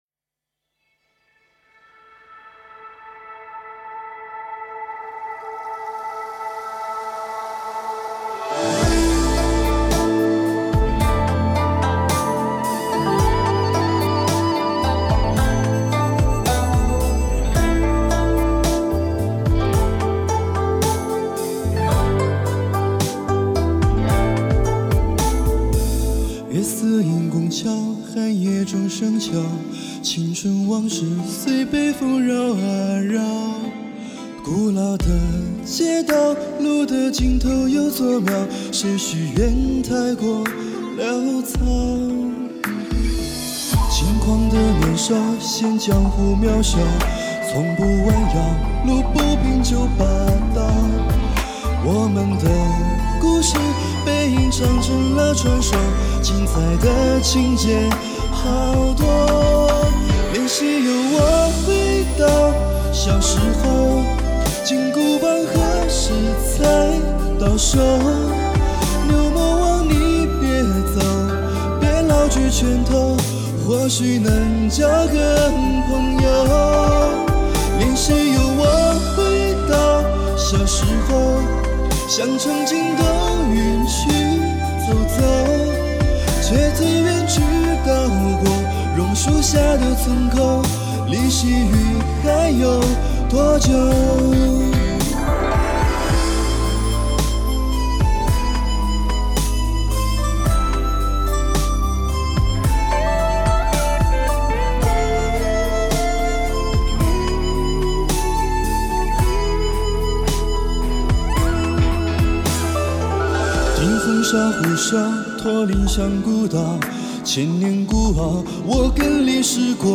♦音乐盛宴♦玩家优秀翻唱作品展示